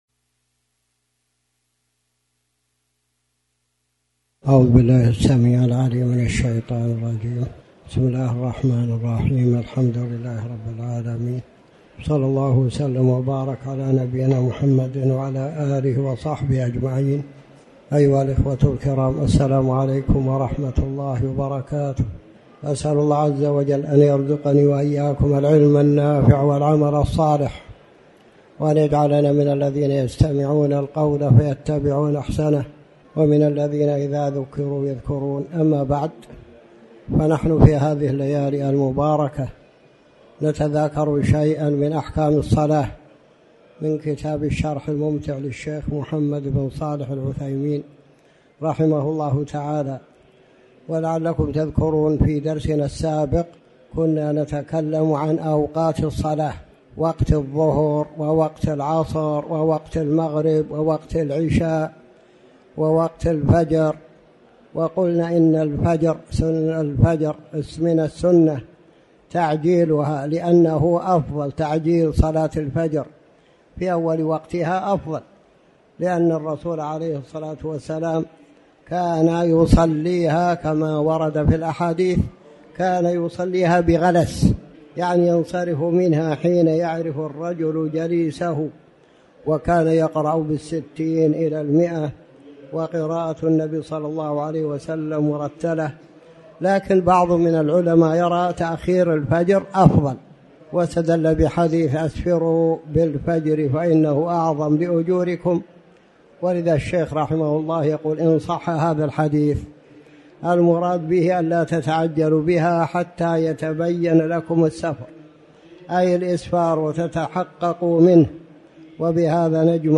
تاريخ النشر ٢٥ صفر ١٤٤٠ هـ المكان: المسجد الحرام الشيخ